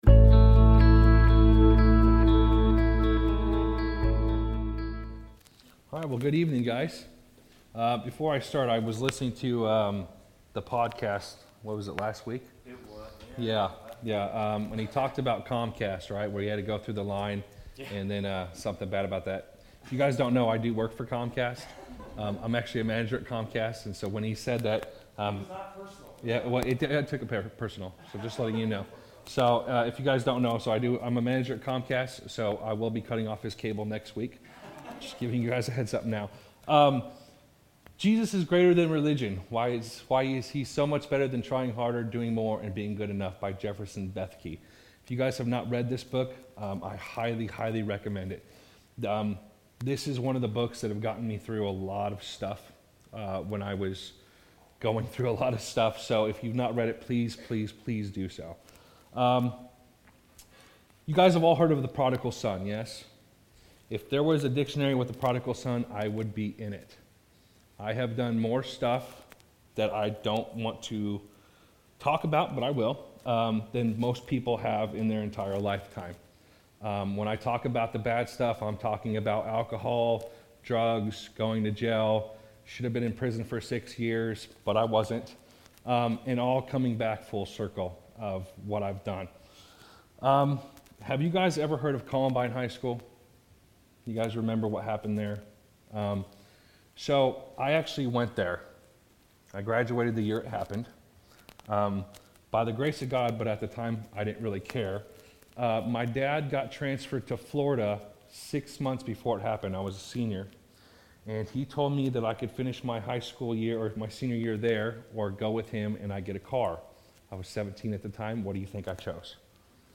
Guest speaker
Sermons